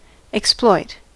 exploit: Wikimedia Commons US English Pronunciations
En-us-exploit.WAV